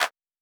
11. ASTROTHUNDER CLAP.wav